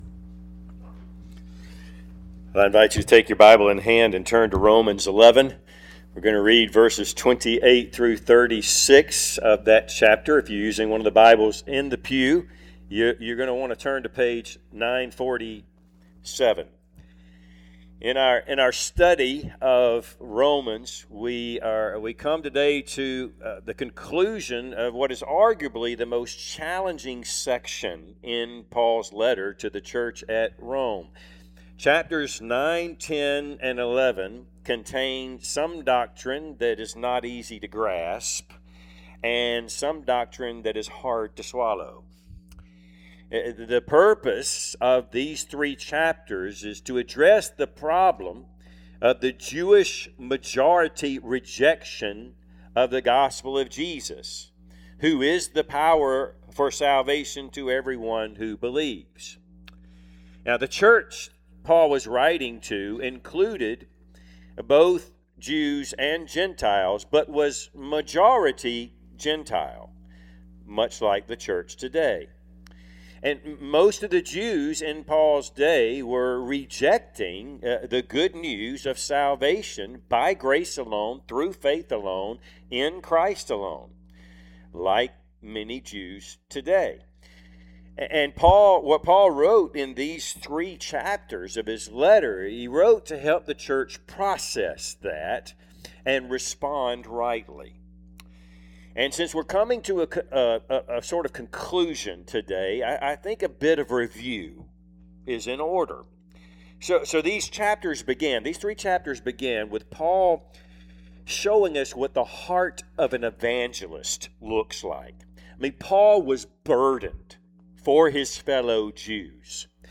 Passage: Romans 11:28-36 Service Type: Sunday AM